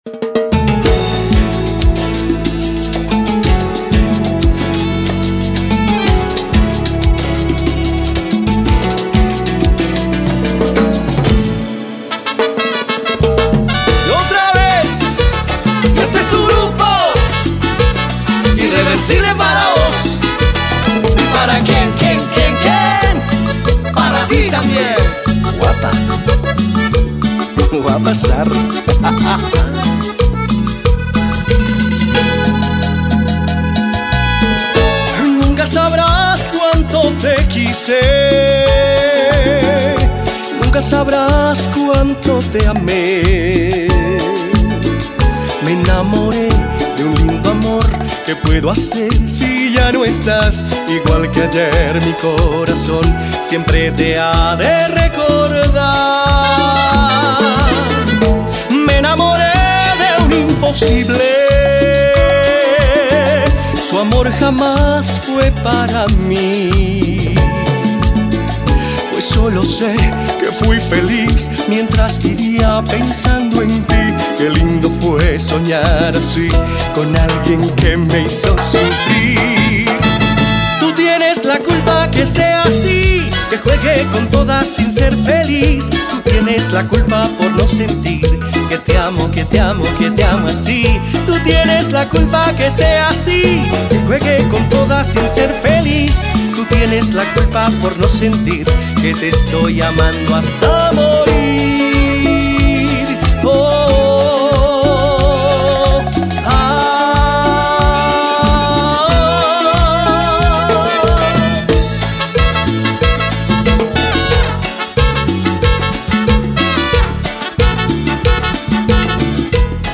Genre Cumbia Latina